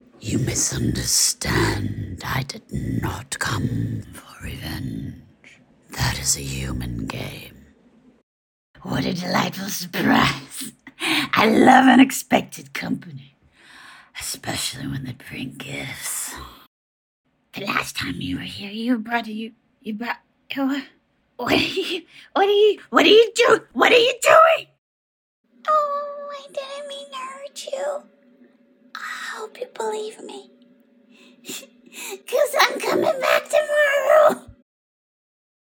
Demo reels
4 Octaves of Growly Beasts
4-Octaves-of-Growly.mp3